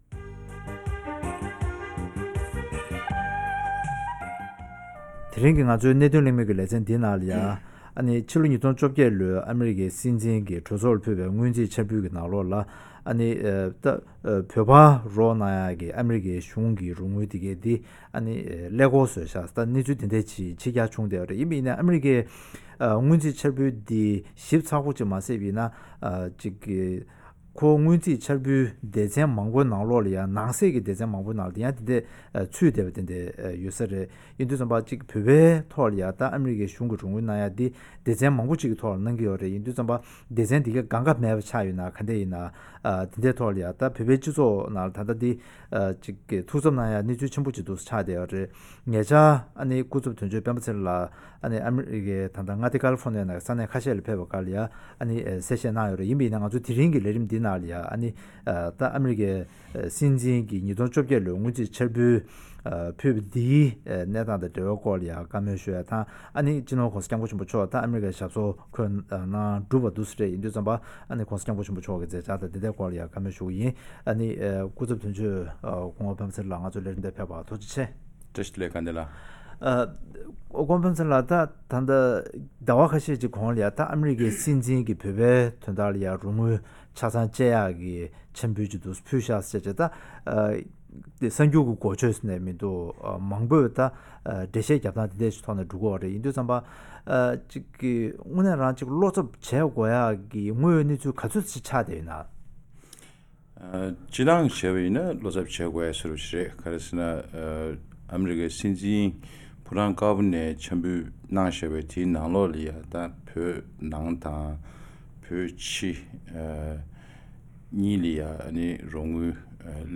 ཐེངས་འདིའི་གནད་དོན་གླེང་མོལ་གྱི་ལེ་ཚན་ནང་དུ།